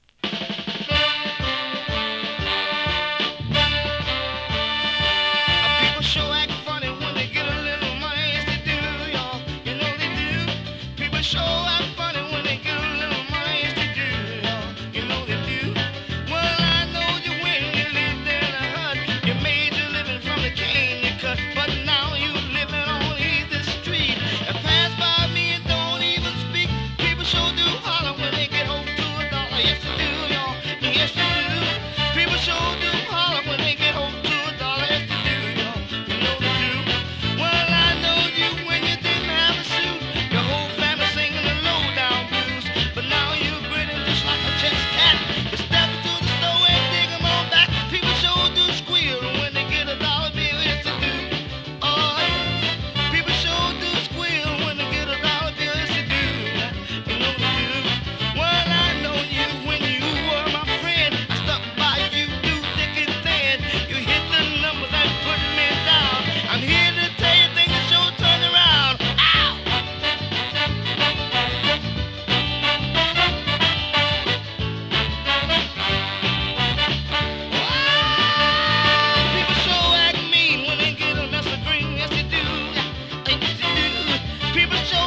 60s R＆B ファンキーソウル